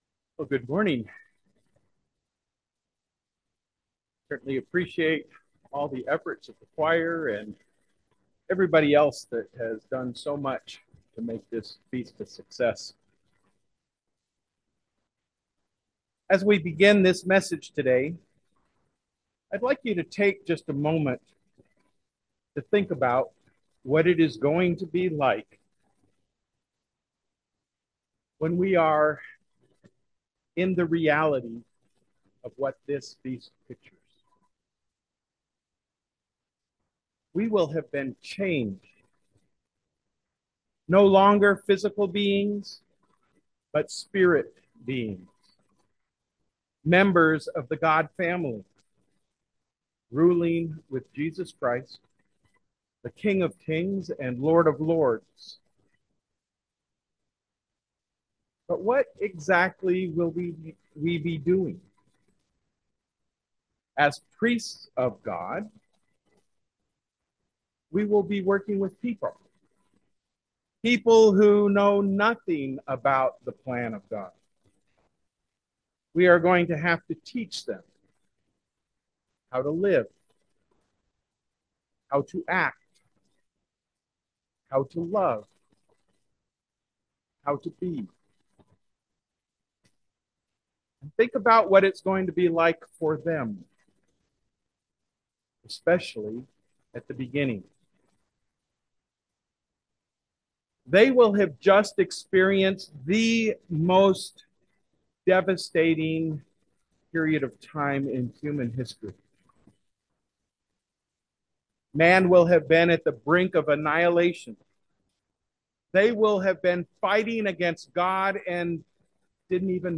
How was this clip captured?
This sermon was given at the Lake Geneva, Wisconsin 2023 Feast site.